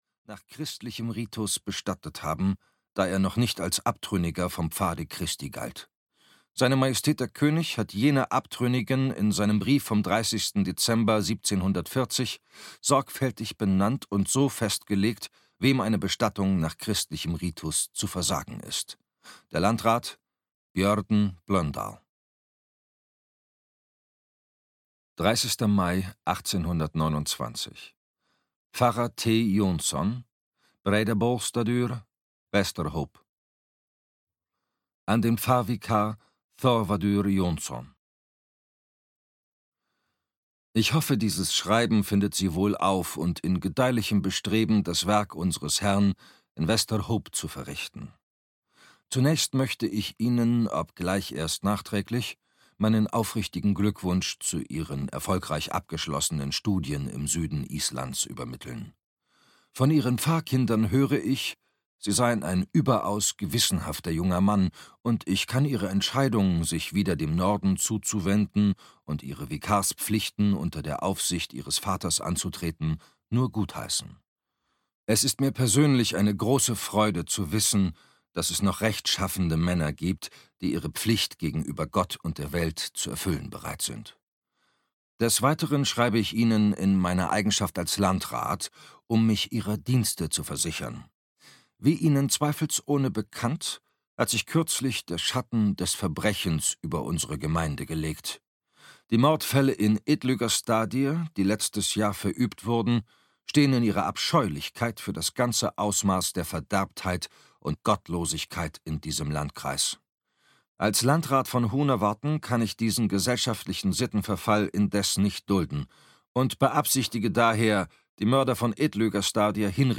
Das Seelenhaus - Hannah Kent - Hörbuch